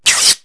highrip.wav